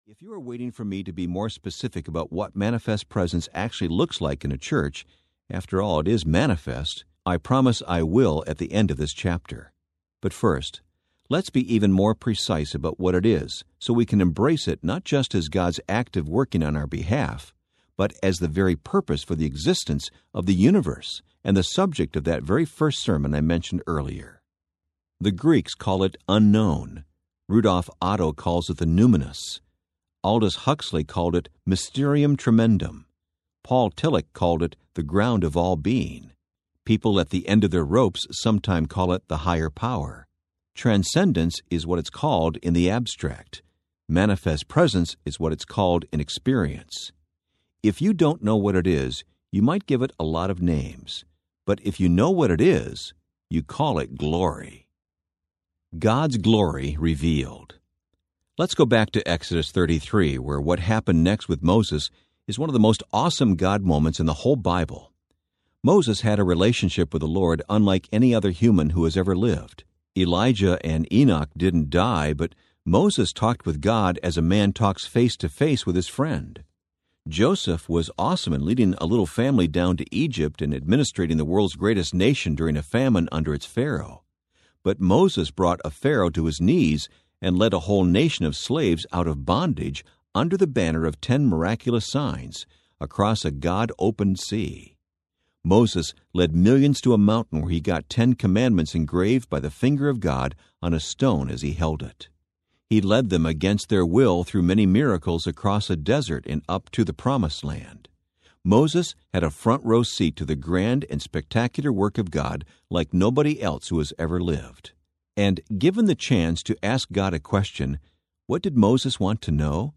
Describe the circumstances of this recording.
8.2 Hrs. – Unabridged